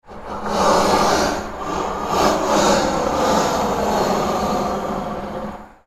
Fire-burner-sound-effect.mp3